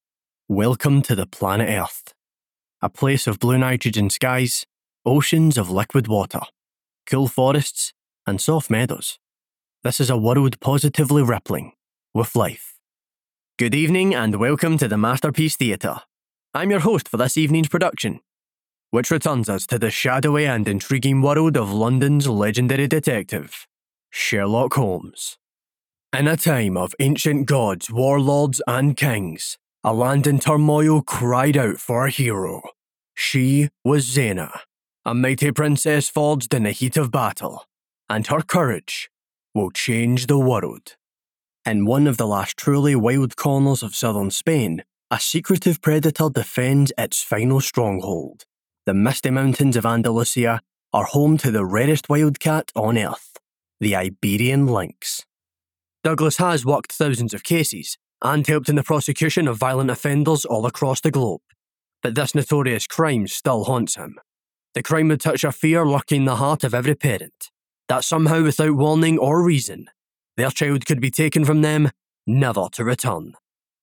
Male
Yng Adult (18-29)
My accent is a Scottish central belt, Glaswegian accent, though clear and informative
To conversational, and inviting , friendly tone
Tv Narration & Documentary
All our voice actors have professional broadcast quality recording studios.